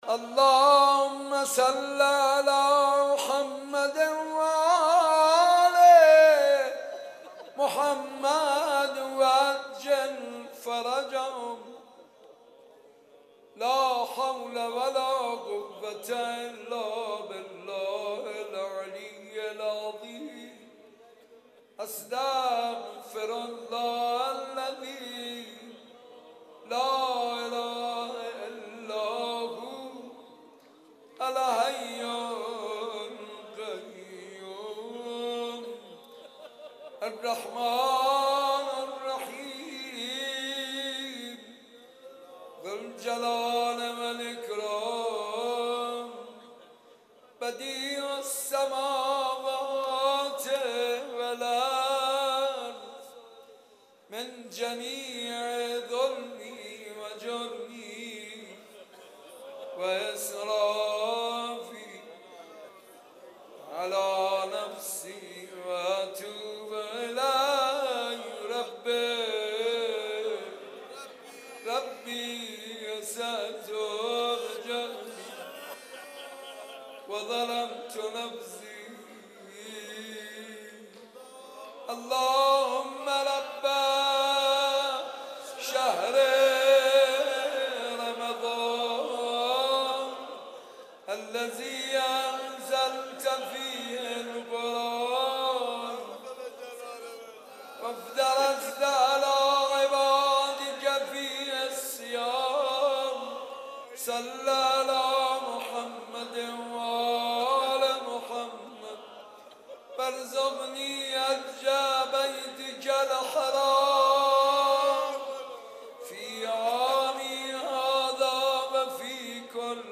ترتیل جزء شانزدهم قرآن کریم